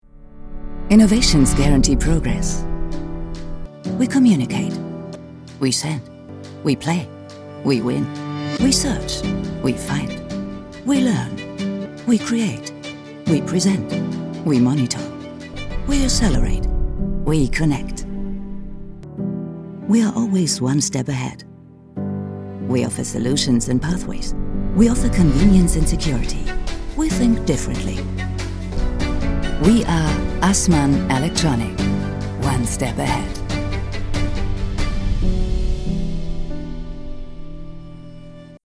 Foreign Language Voice Samples
Corporate Videos
I am a professional German voice actress for dubbing, TV and radio with recording options including SessionLinkPro, Source Connect now and ipDTL.
Neumann U87 mic, RME UC Audio interface, professionel speaker booth, Protools, SesssionLinkPro, Source connect now, Teams, Zoom